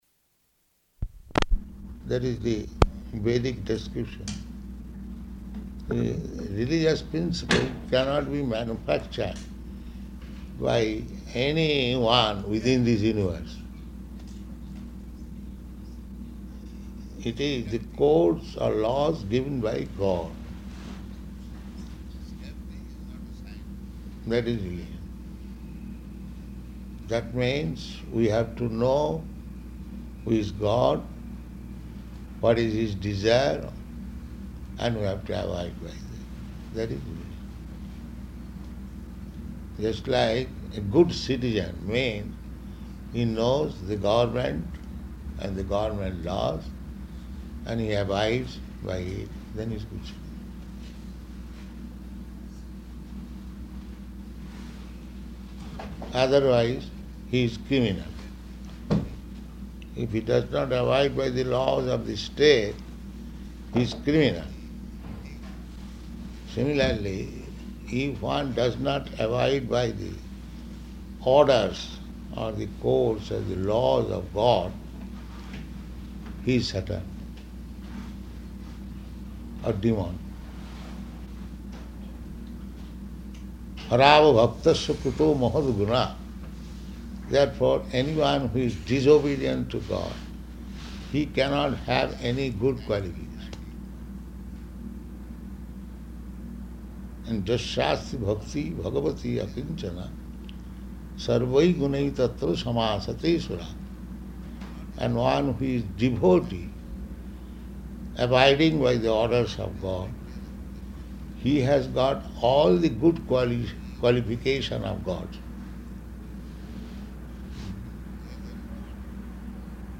Room Conversation with three Trappist Monks
Room Conversation with three Trappist Monks --:-- --:-- Type: Conversation Dated: March 1st 1975 Location: Atlanta Audio file: 750301R1.ATL.mp3 Prabhupāda: That is the Vedic description.